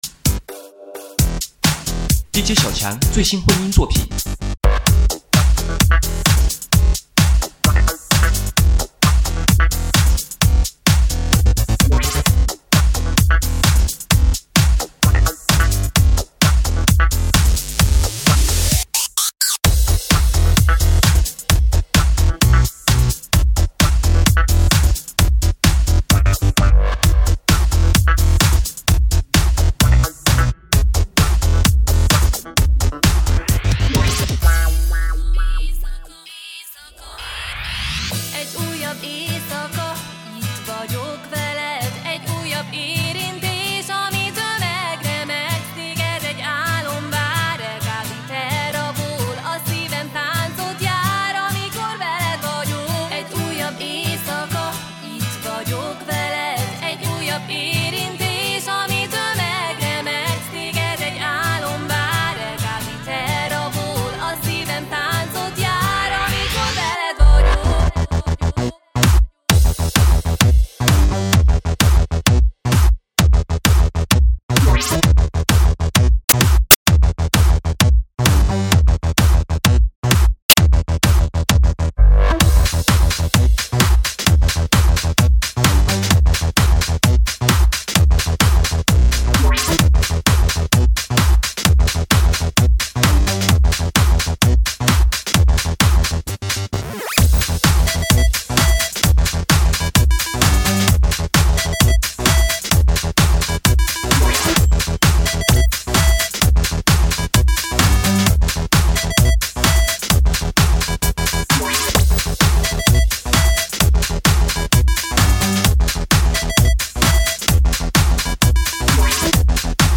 低品质试听